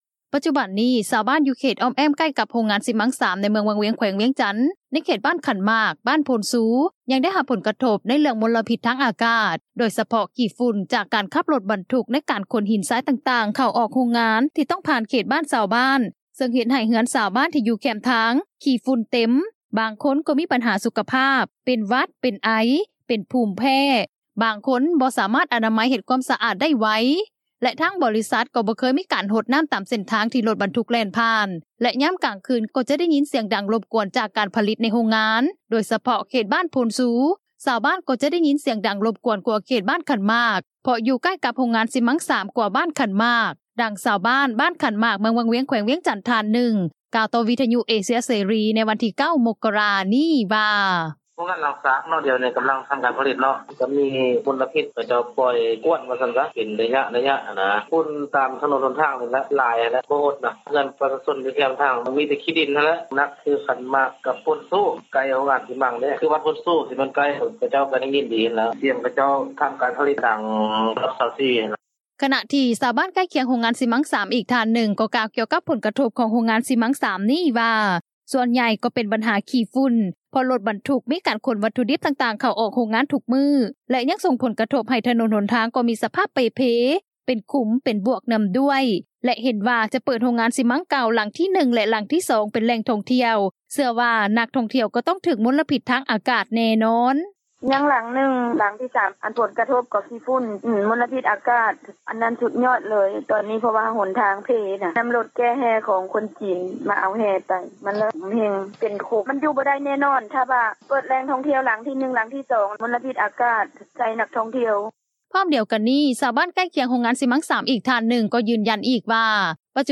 ດັ່ງຊາວບ້ານ ບ້ານຂັນໝາກ ເມືອງວັງວຽງ ແຂວງວຽງຈັນ ທ່ານນຶ່ງ ກ່າວຕໍ່ວິທຍຸເອເຊັຽເສຣີ ໃນວັນທີ 9 ມົກກະຣາ ນີ້ວ່າ: